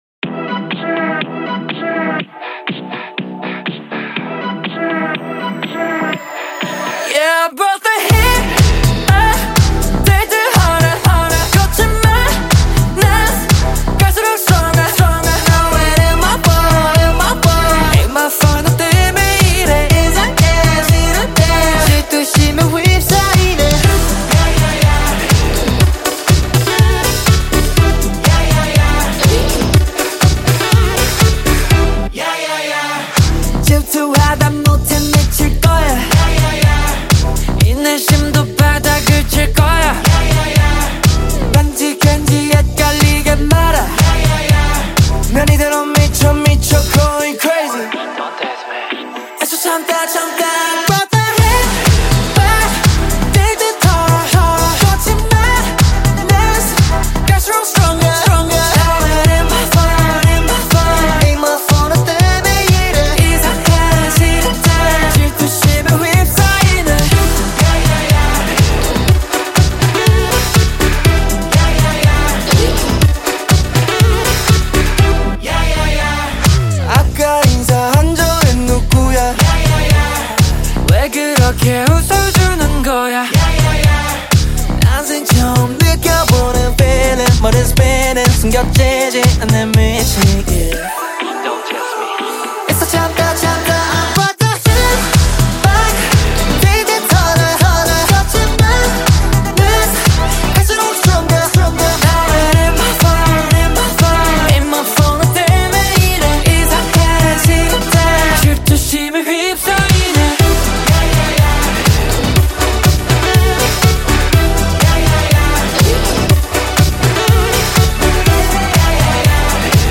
KPop Song
Label Dance